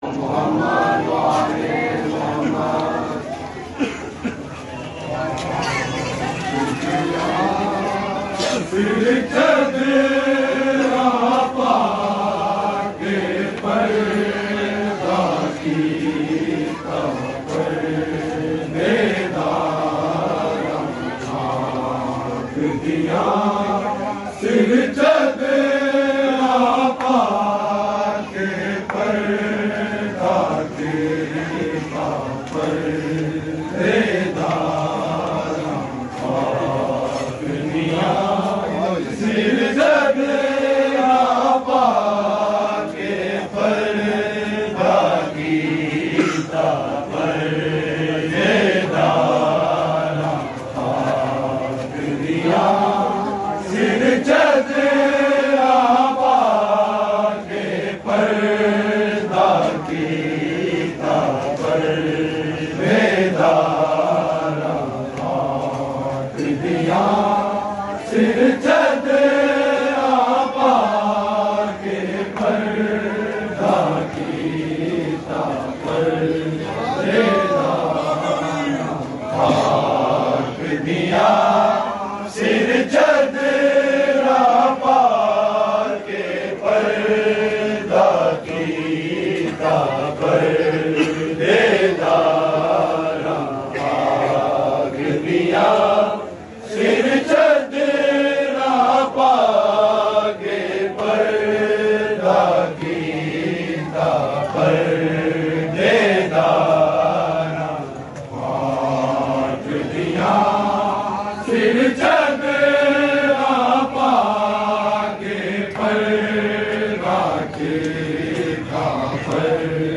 Recording Type: Live
Location: Mohalla Shia Andron Mochi Gate Lahore